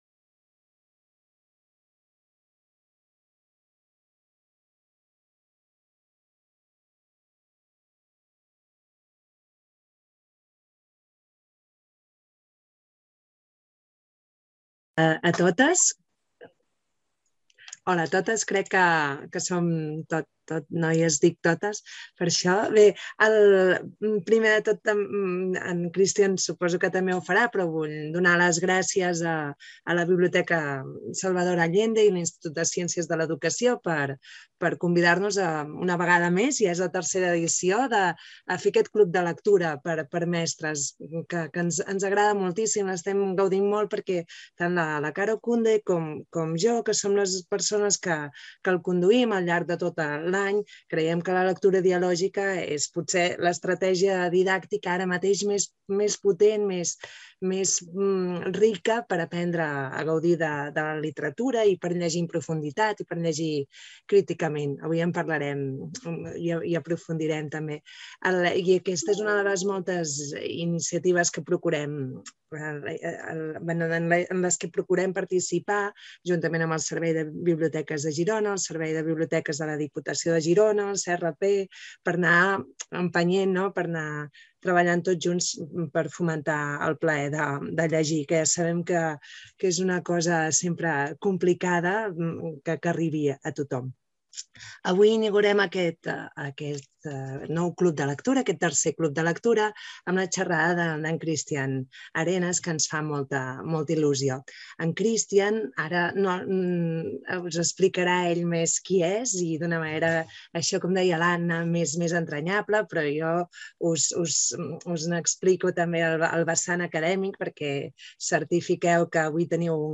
Conferència